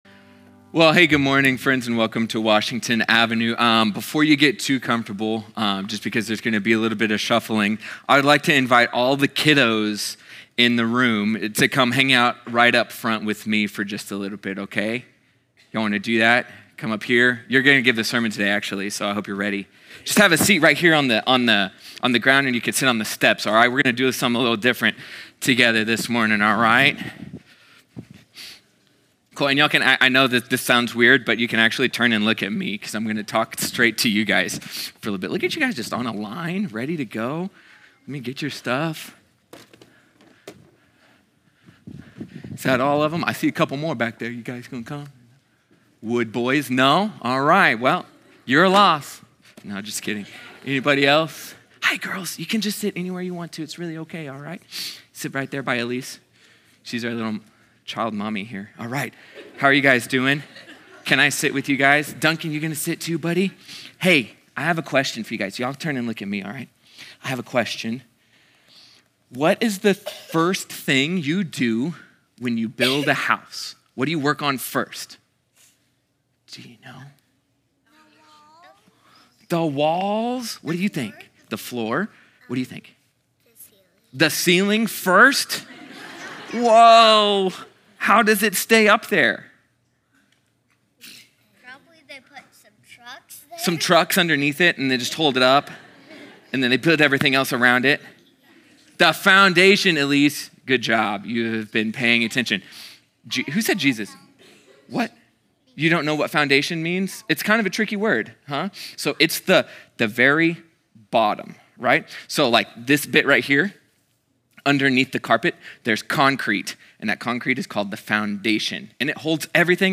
sermon audio 0831.mp3